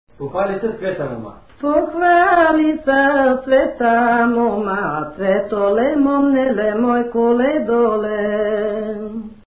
музикална класификация Песен
форма Едноредична с рефрен (R)
размер Седем шестнадесети
фактура Едногласна
начин на изпълнение Солово изпълнение на песен
битова функция Коледарска
фолклорна област Северозападна България
място на записа Рупци
начин на записване Магнетофонна лента